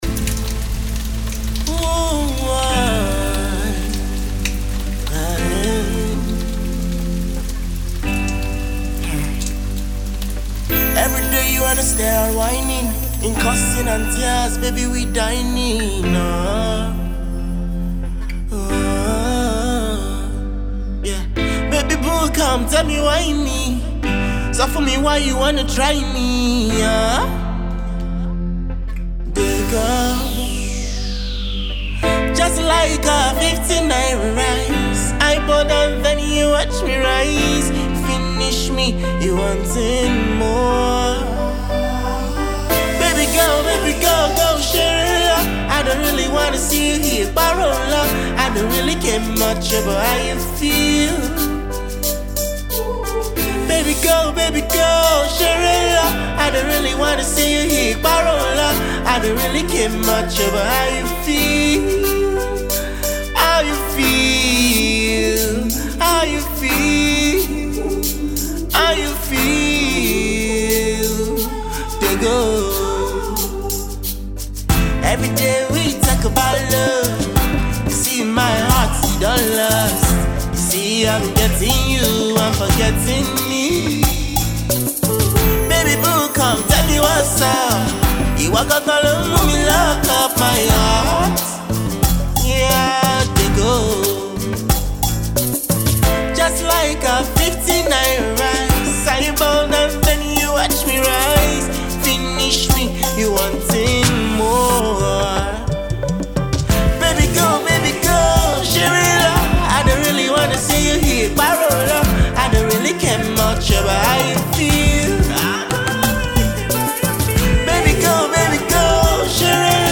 African youth’s love song